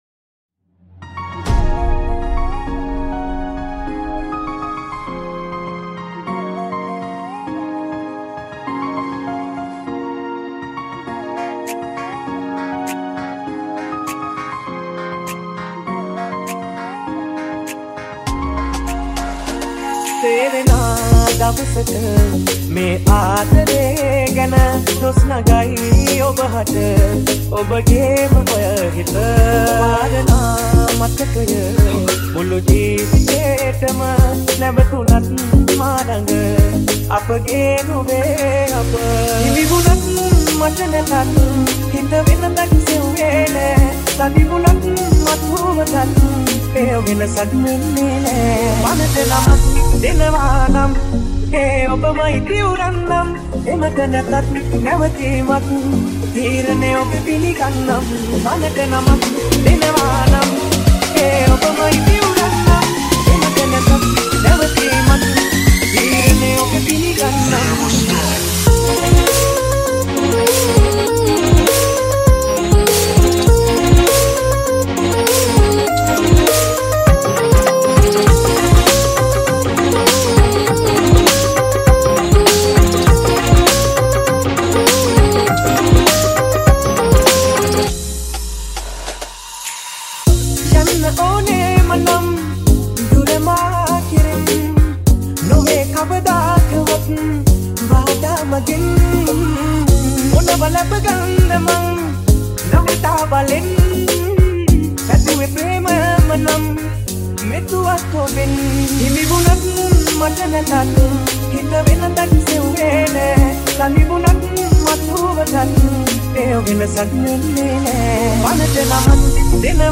Dj Song Remix